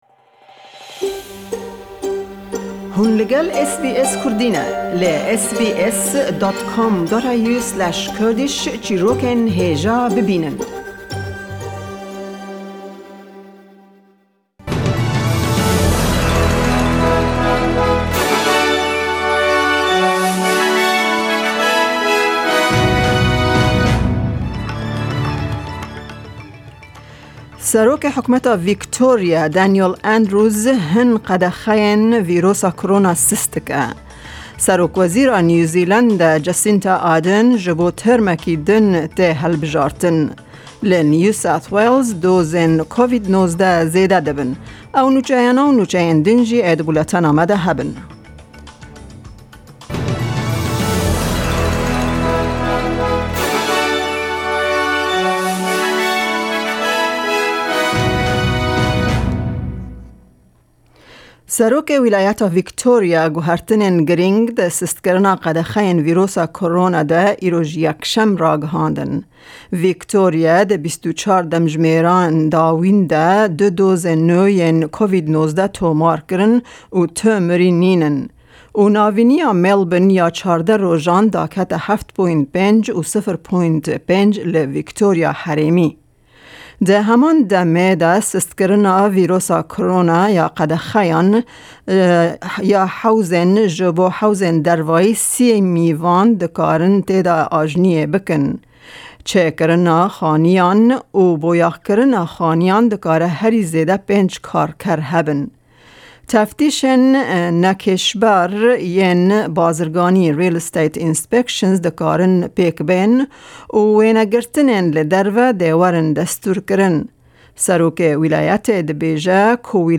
Buletena Nûçeyên roja Yekşemê 18/10/2020 demjimêr 2:00 p.n. bi Dema Rojhilatî Australya.